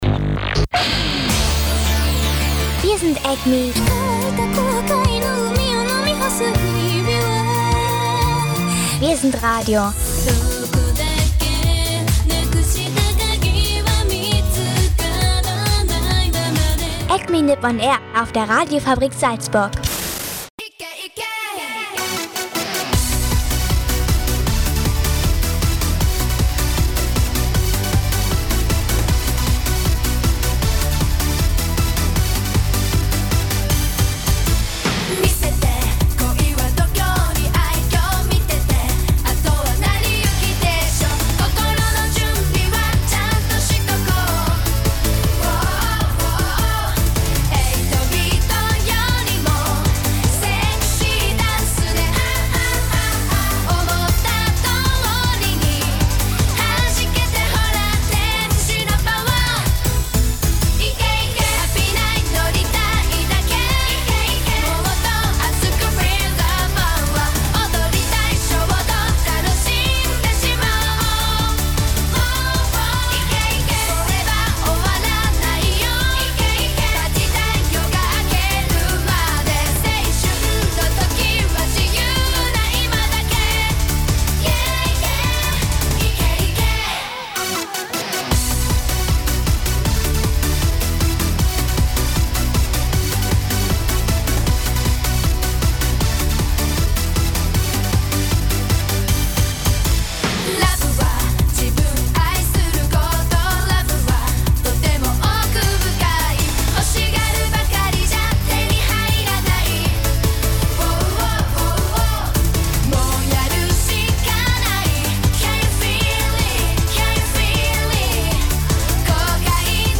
Außerdem gibt es viele Interviews mit Besuchern der Convention. Und natürlich aktuelle News aus Japan, der Anime-Szene und das Wetter.